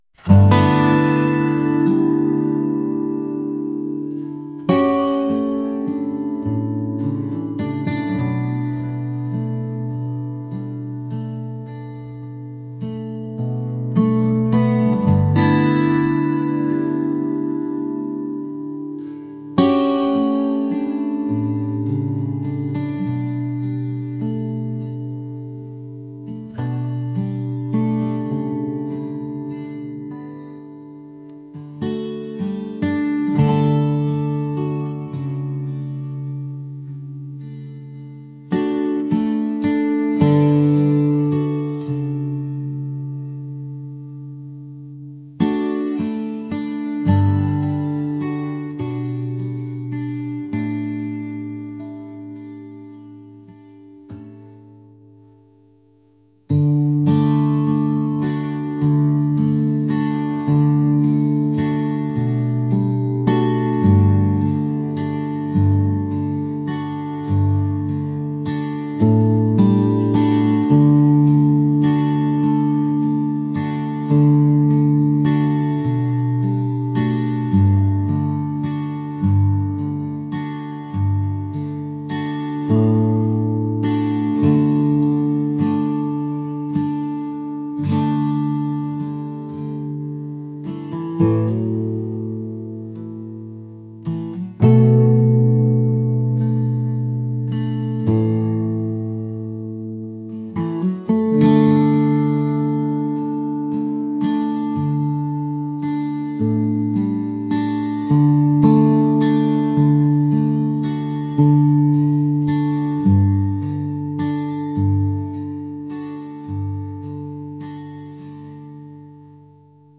folk | acoustic